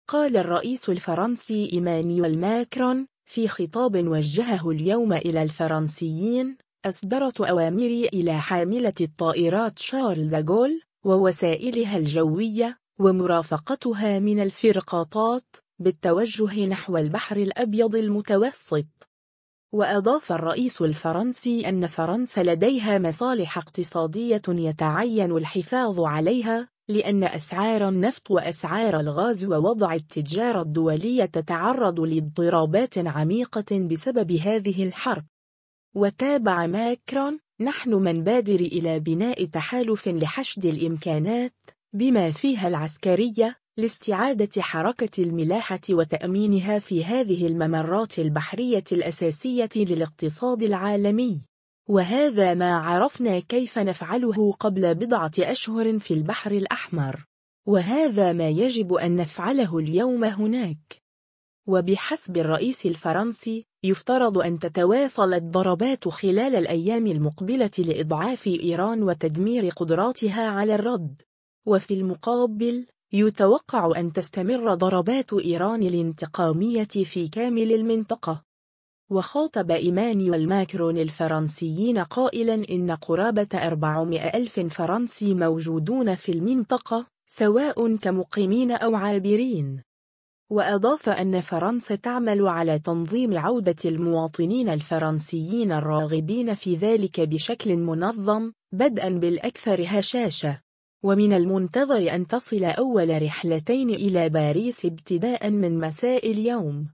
قال الرئيس الفرنسي إيمانويل ماكرون، في خطاب وجّهه اليوم إلى الفرنسيين: «أصدرتُ أوامري إلى حاملة الطائرات شارل ديغول، ووسائلها الجوية، ومرافقتها من الفرقاطات، بالتوجّه نحو البحر الأبيض المتوسط».